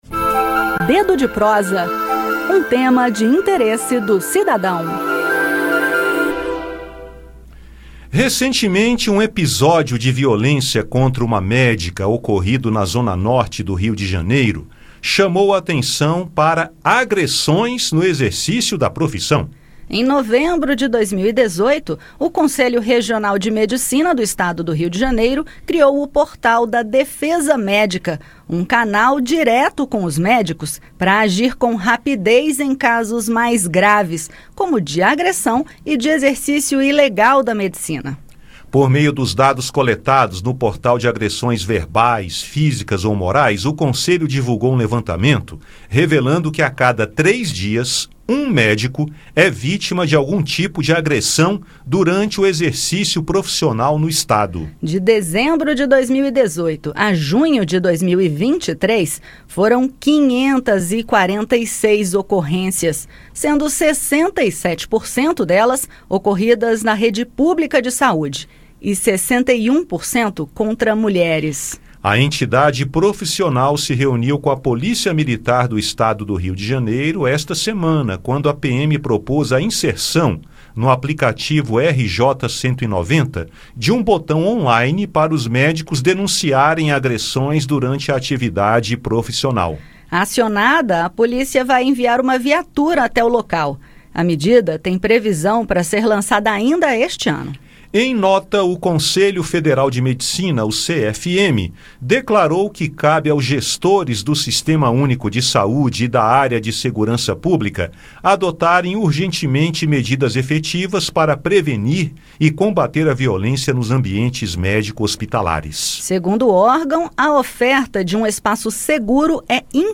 Nesta semana, a entidade profissional se reuniu com a polícia militar e propôs a inserção, no aplicativo RJ 190, um acesso on-line para os médicos denunciarem agressões durante suas atividades. Saiba mais no bate-papo.